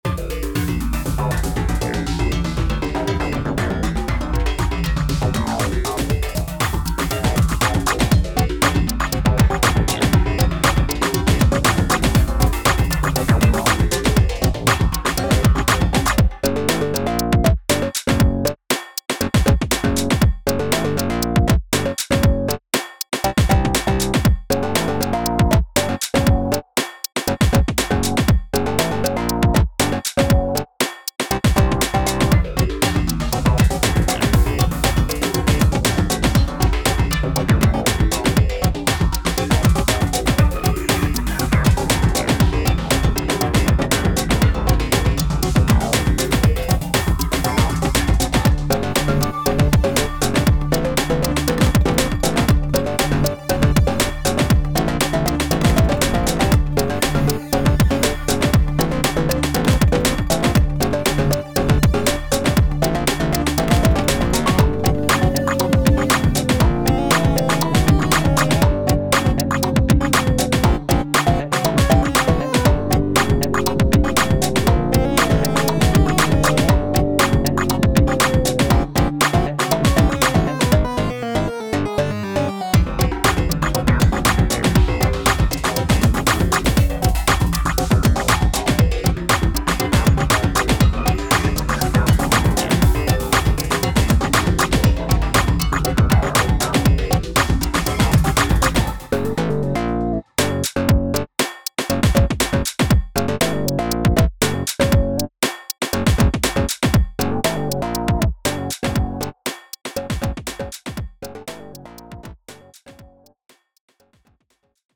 タグ: Beat EDM 不気味/奇妙 変わり種 電子音楽 コメント: 様子がおかしい工場をイメージしたBGM。